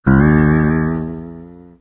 spring.ogg